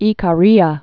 (ēkä-rēä) also I·car·i·a (ĭ-kârē-ə, ī-kâr-)